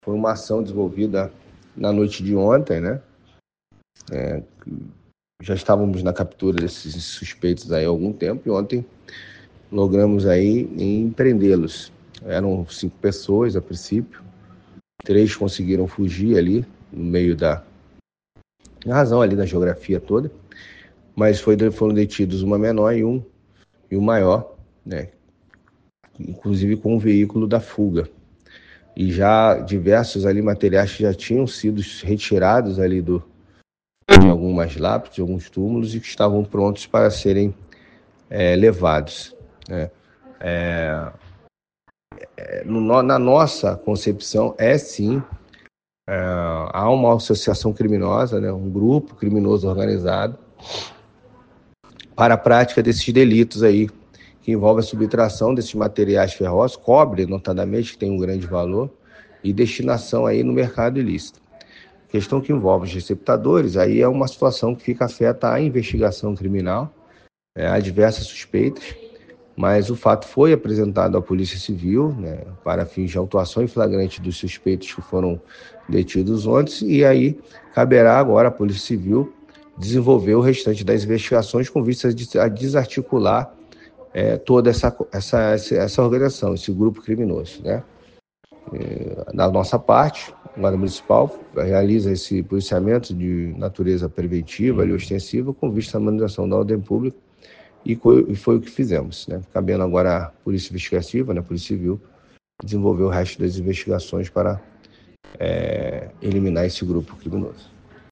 O delegado Luiz Alves, secretário de Segurança Pública, falou sobre o assunto. Ele disse que acredita tratar-se de uma organização criminosa.